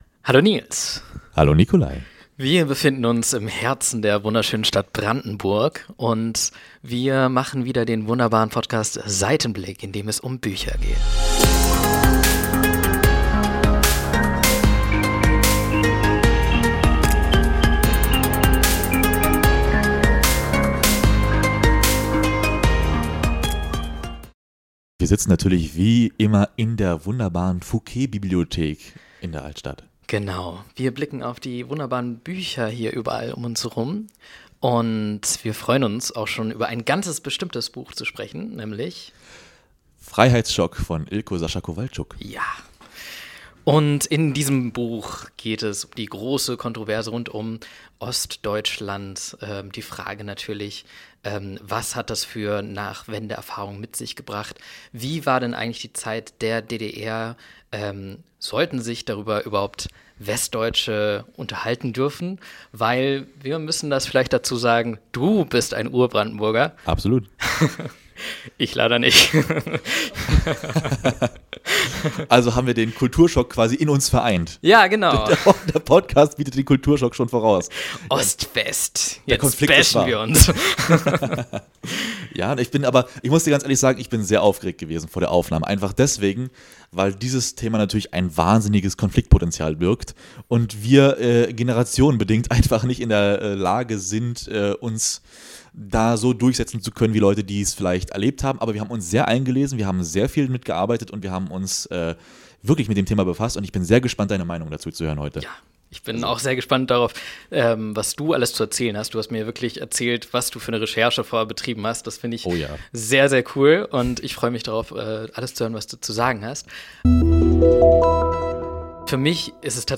Ein emotionaler und aufschlussreicher Dialog über Freiheit, Identität und die langen Schatten der Geschichte.